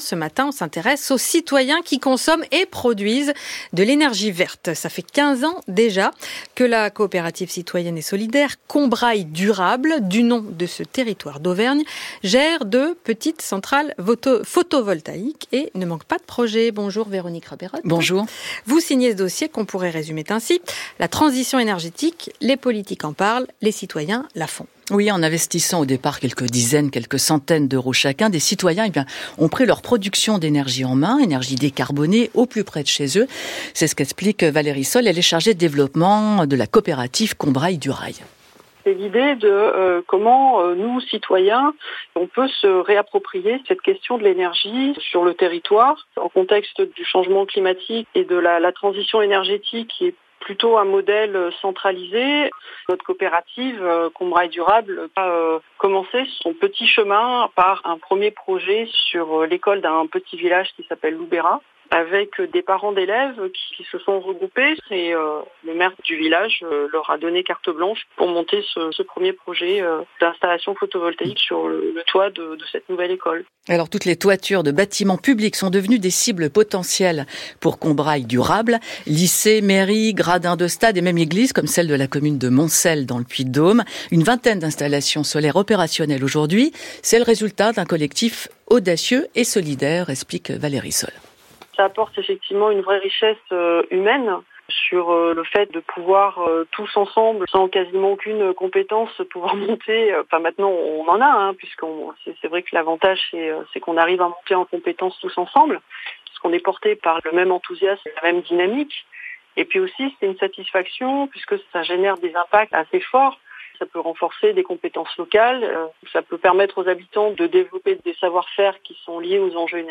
Un reportage et une interview inspirante à découvrir ci-dessous